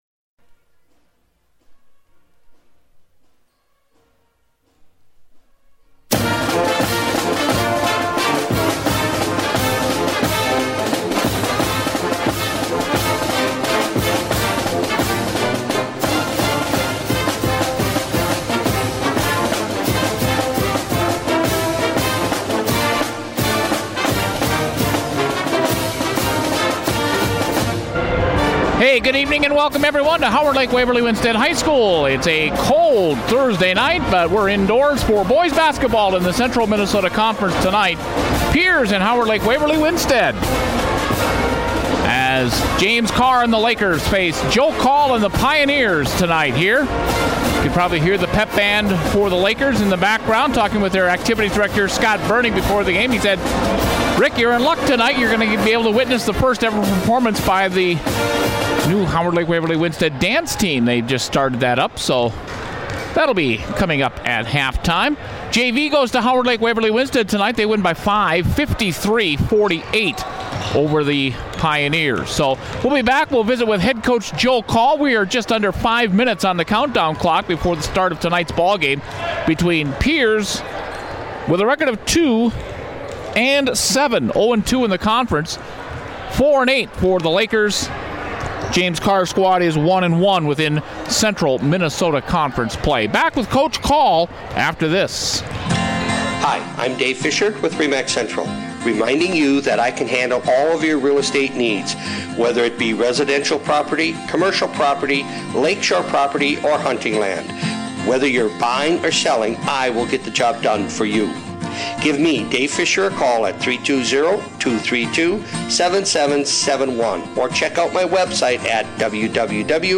Pierz Pioneers at HLWW Lakers Boys Basketball 2018